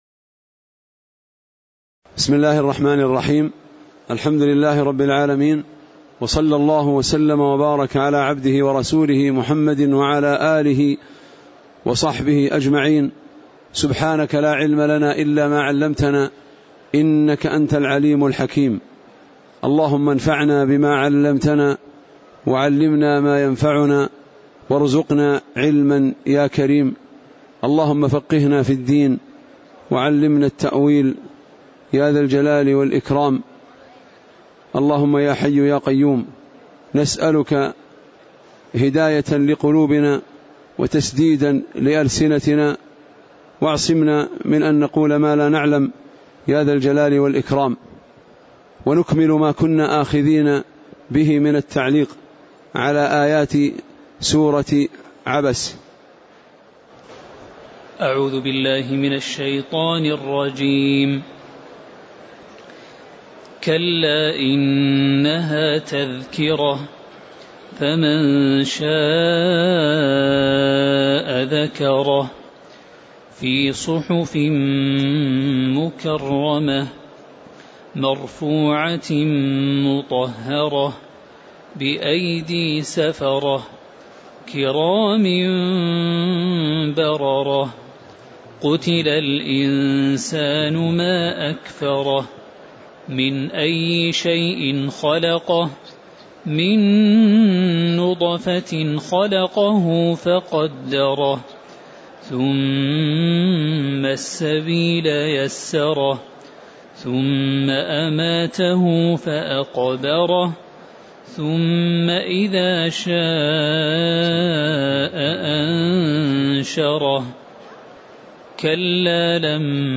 تاريخ النشر ١٤ شوال ١٤٣٨ هـ المكان: المسجد النبوي الشيخ